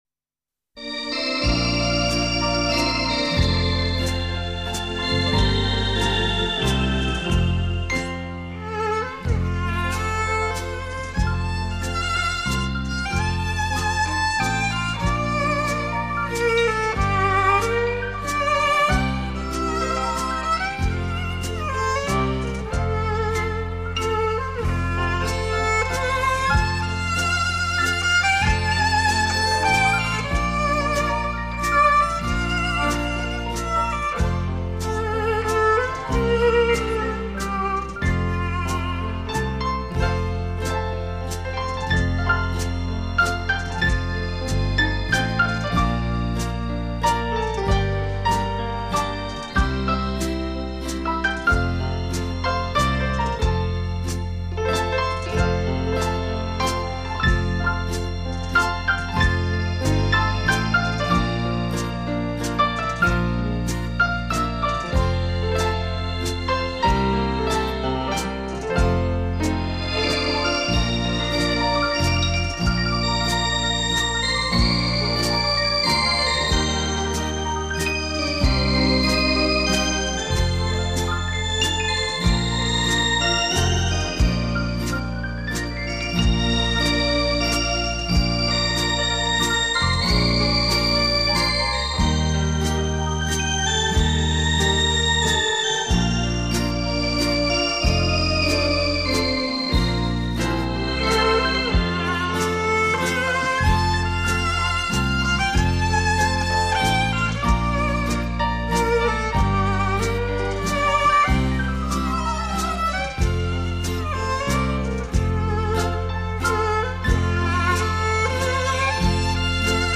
[本专辑包括英国华尔兹和维也纳华尔兹]